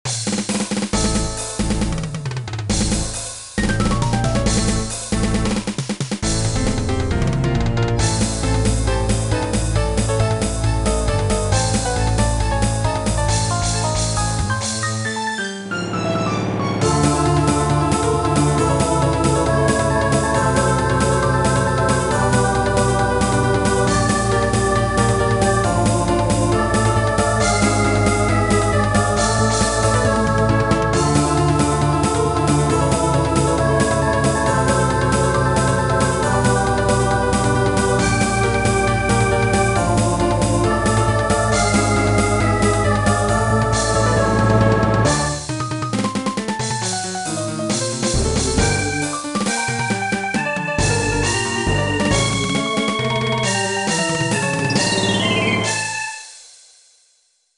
MIDIやってみた。